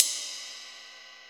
CUP RIDE 1.wav